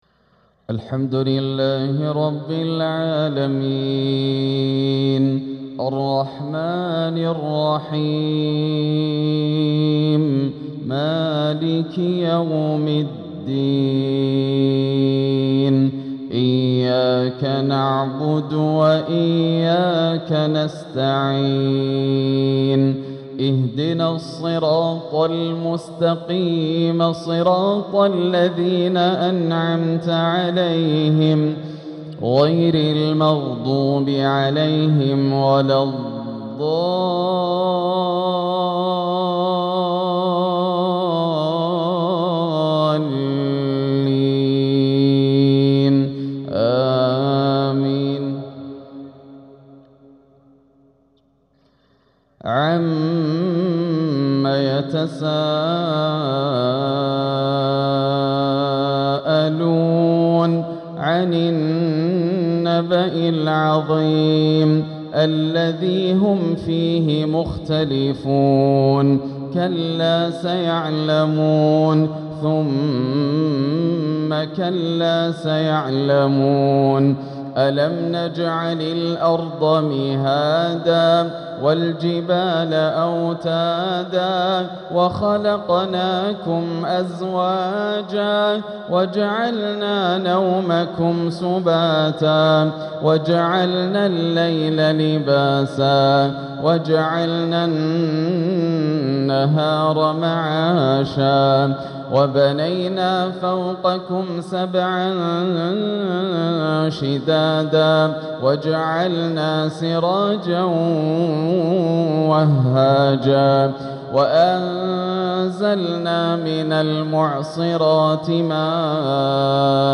تلاوة مميزة لسورة النبأ | عشاء الاثنين 3-2-1447هـ > عام 1447 > الفروض - تلاوات ياسر الدوسري